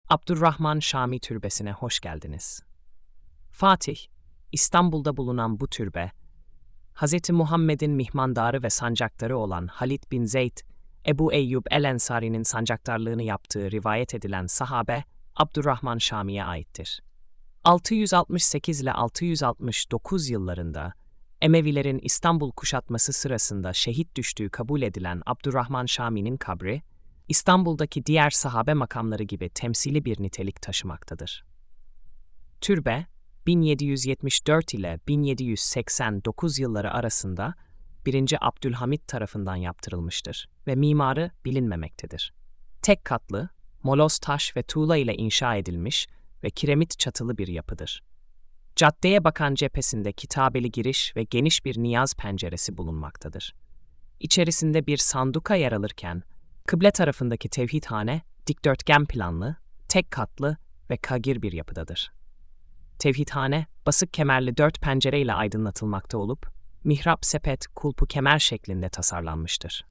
SESLİ ANLATIM: